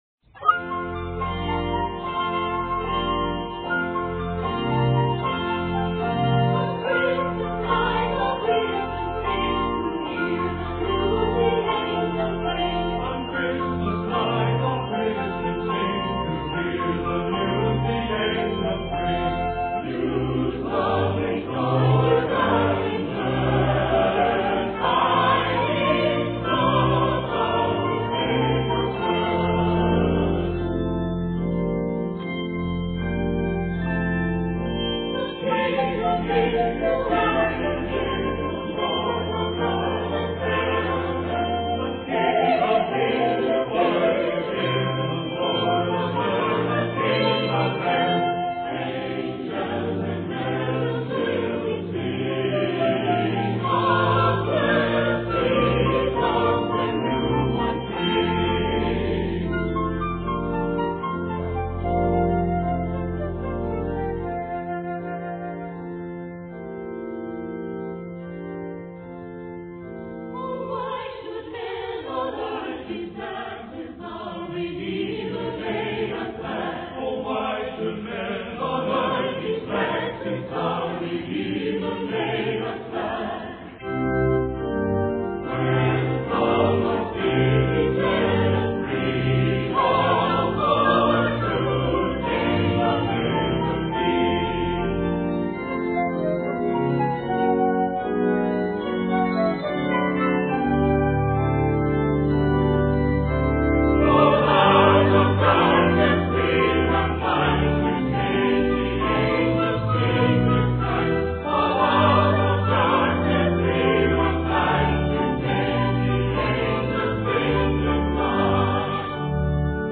A rollicking arrangement
for SATB Choir, keyboard, optional handbells and/or flute.